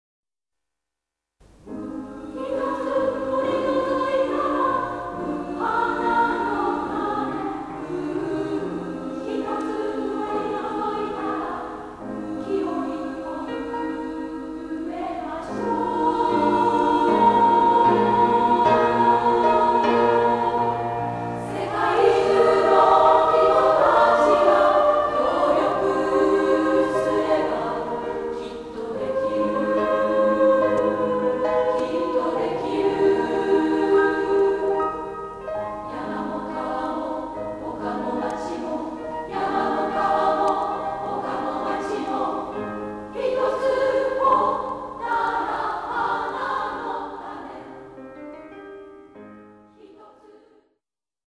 楽譜販売中：女声合唱組曲「地雷ではなくをください